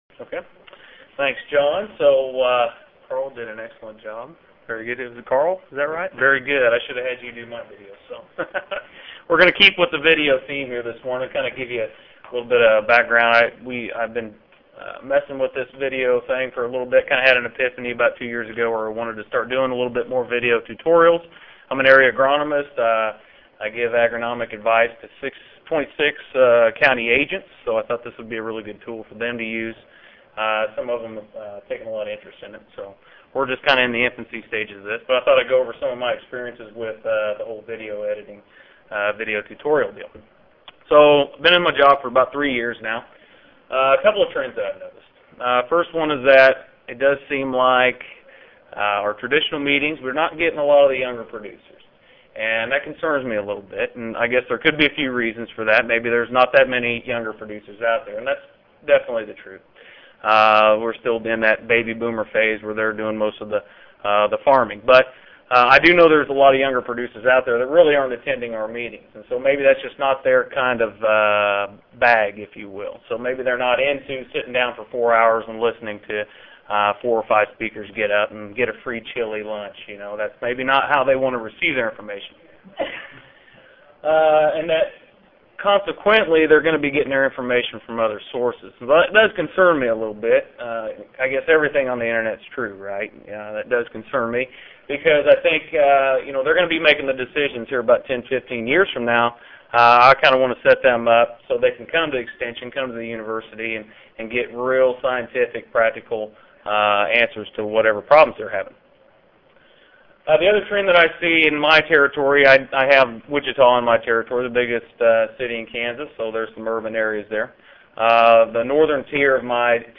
KS Audio File Recorded presentation We are entering a new phase of communication with the emergence of popular video websites such as YouTube.